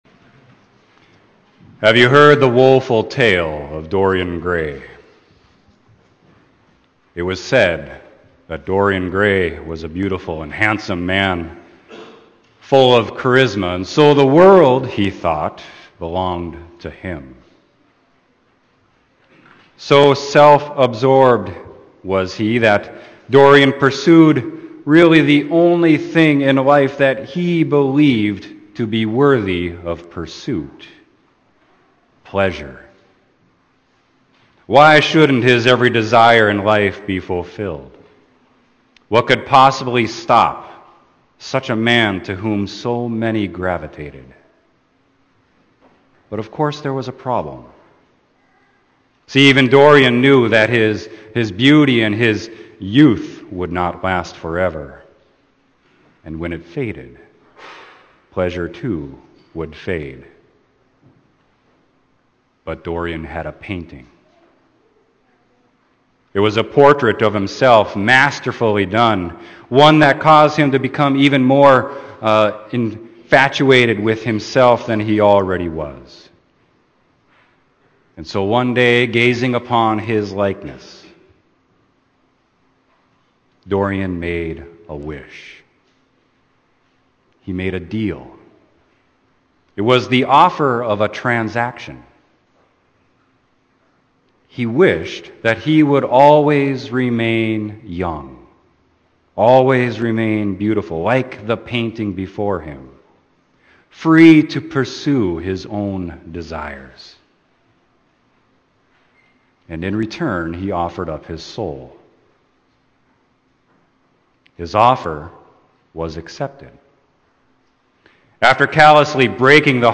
Sermon: Romans 3:19-28